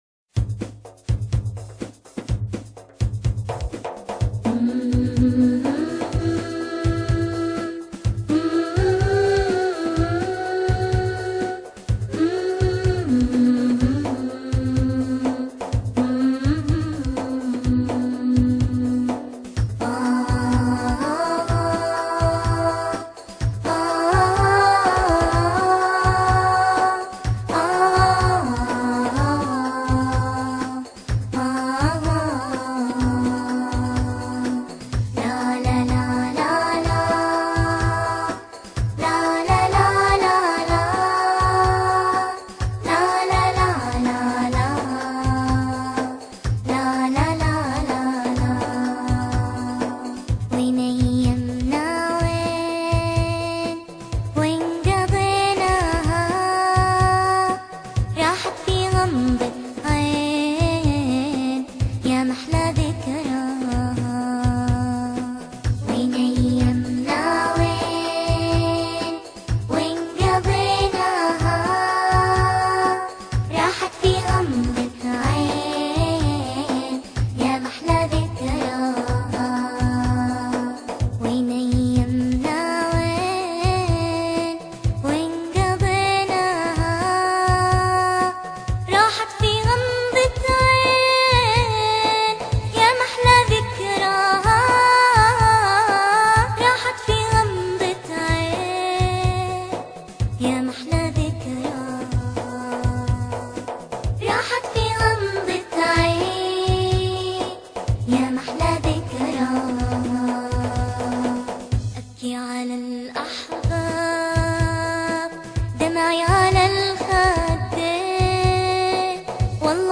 انشودة